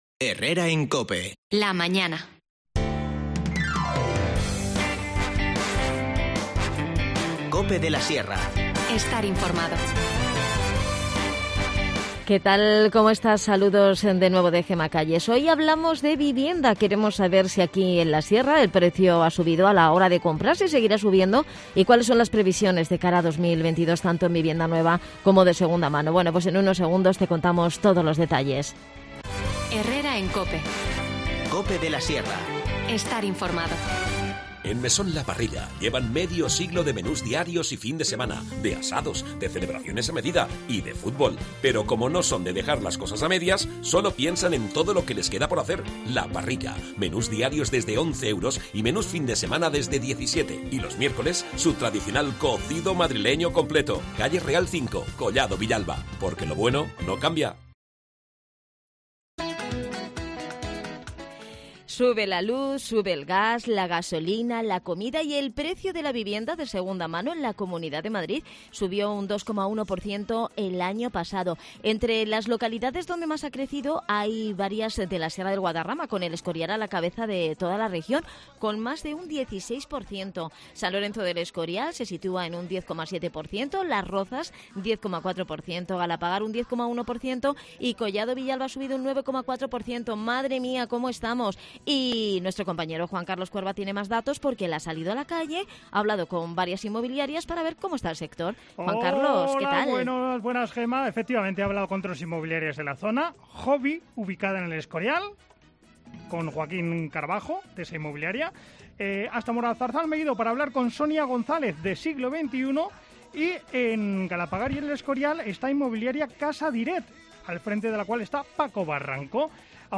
Hemos querido analizar cómo está el sector en la Sierra de Guadarrama y cuáles serán las previsiones para 2022 . Para ello hablamos con 3 inmobiliarias ubicadas en la Sierra: Jovy, Siglo XXI y Casa Direct Toda la actualidad en Cope de la Sierra Escucha ya las desconexiones locales de COPE de la Sierra e n Herrera en COPE de la Sierra y Mediodía COPE de la Sierra .